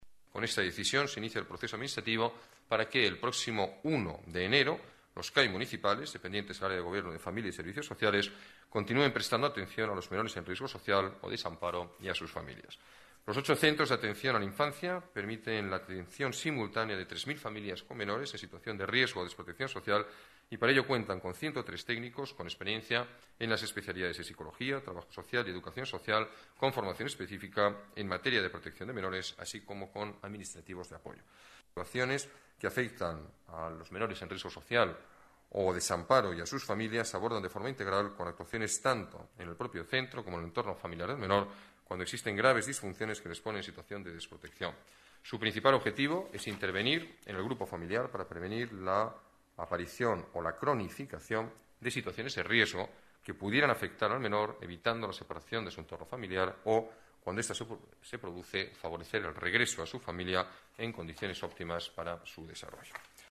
Nueva ventana:Declaraciones del alcalde de Madrid, Alberto Ruiz-Gallardón: Cinco Centros de Atención a menores